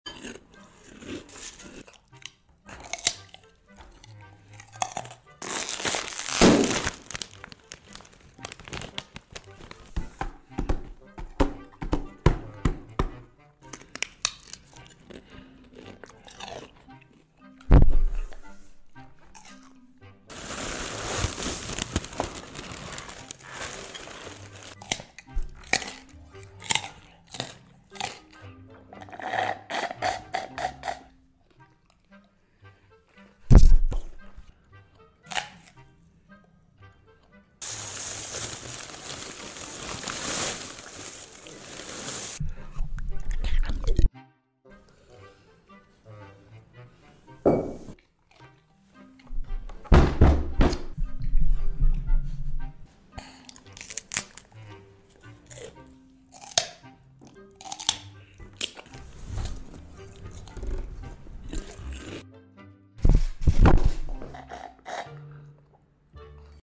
Animalia's Orangutan Rambo crunches corn